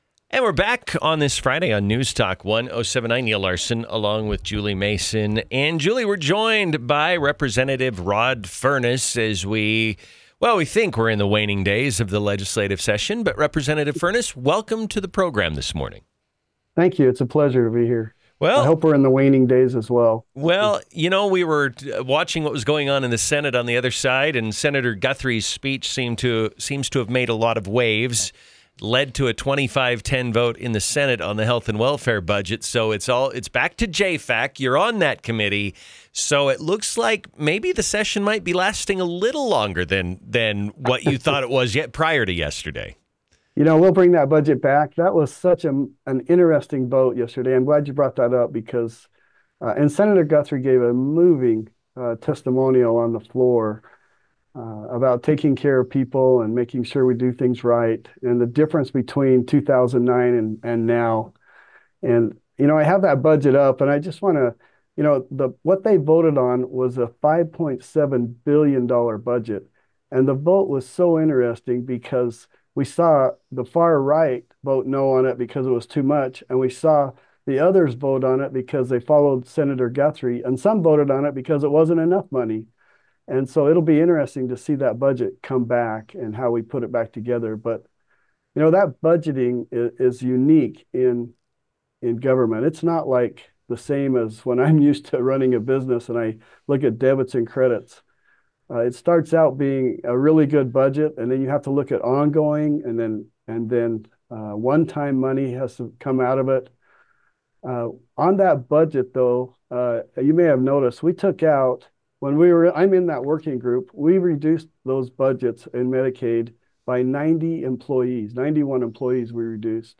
INTERVIEW: Rep Rod Furniss - Budget negotiations stall after Senate vote - Newstalk 107.9
In an interview on Newstalk 107.9, Representative Rod Furniss, a member of the Joint Finance-Appropriations Committee (JFAC), discussed the implications of the 25-10 vote and the challenges facing budget writers as they return to the drawing board.